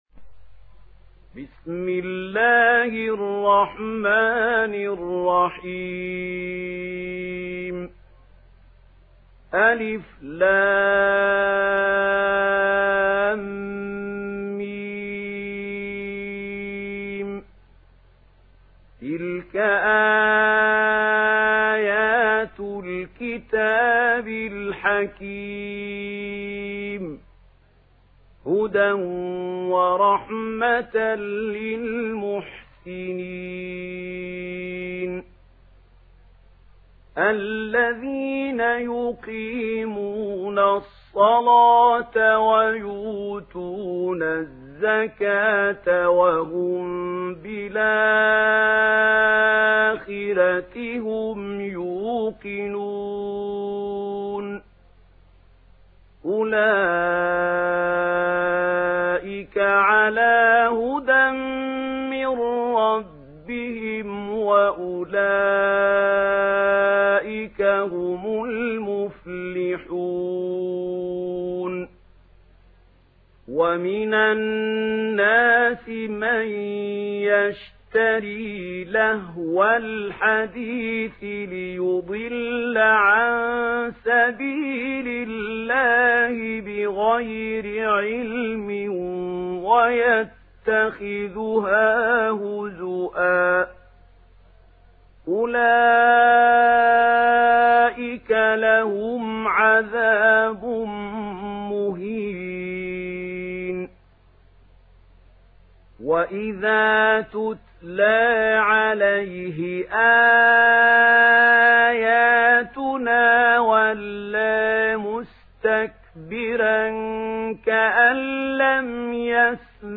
সূরা লুক্বমান ডাউনলোড mp3 Mahmoud Khalil Al Hussary উপন্যাস Warsh থেকে Nafi, ডাউনলোড করুন এবং কুরআন শুনুন mp3 সম্পূর্ণ সরাসরি লিঙ্ক